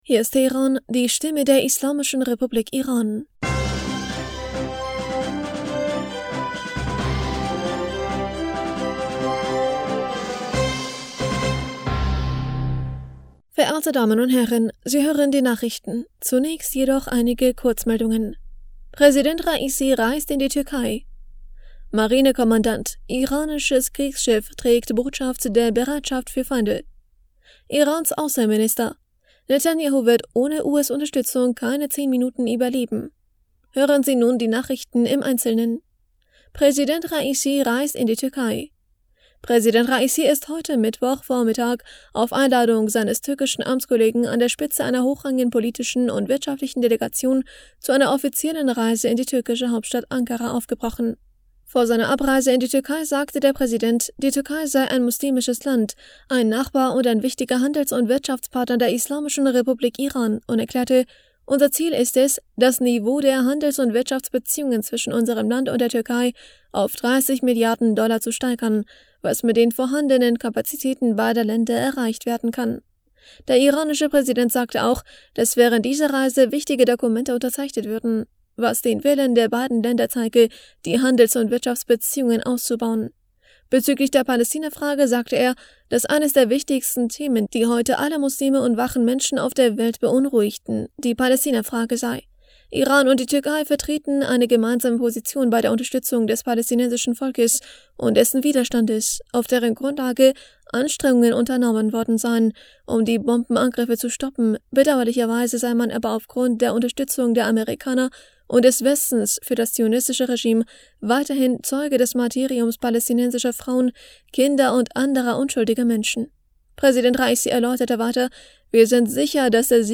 Nachrichten vom 24. Januar 2024